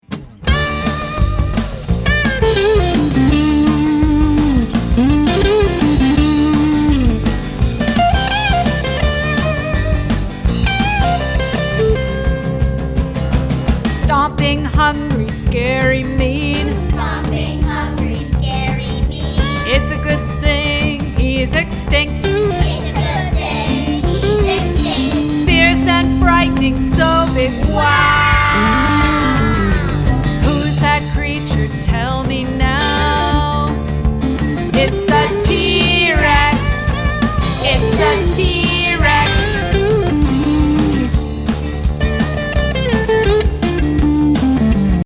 A fun and energizing song for stomping and singing along.